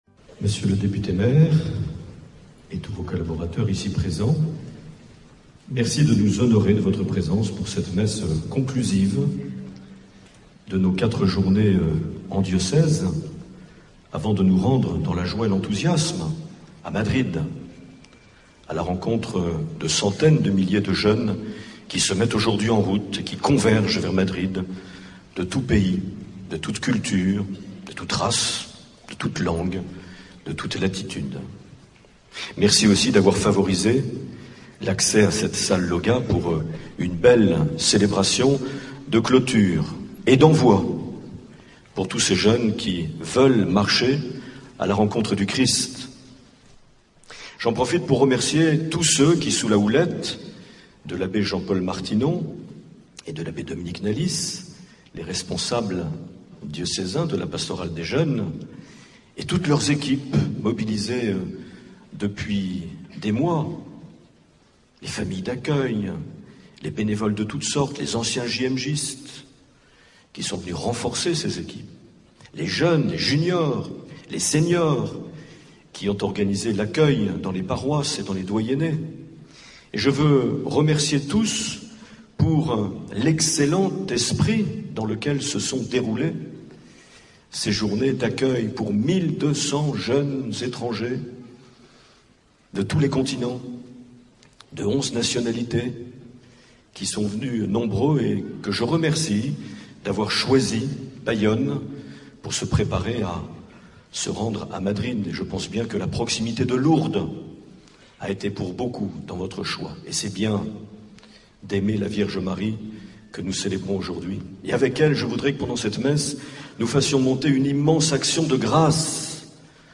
15 août 2011 - Salle Lauga de Bayonne - Assomption de la Vierge Marie et JMJ